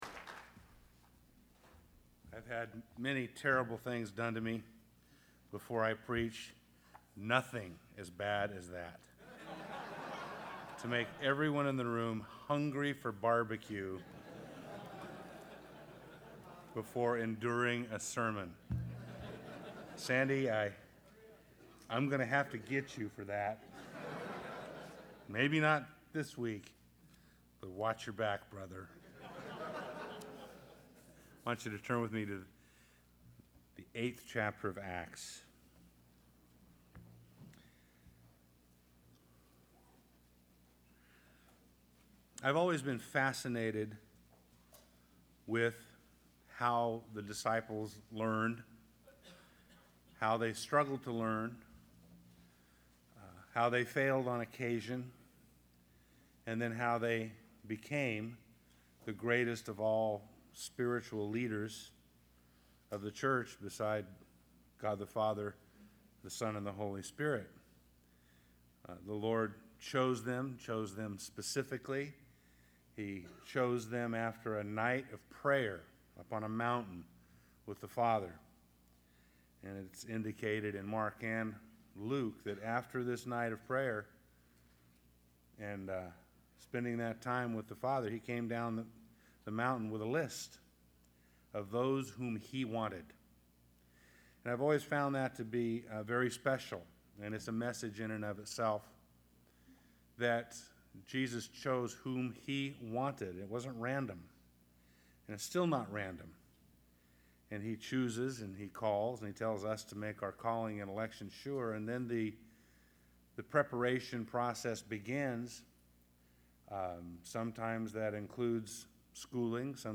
2008 Home » Sermons » Session 5 Share Facebook Twitter LinkedIn Email Topics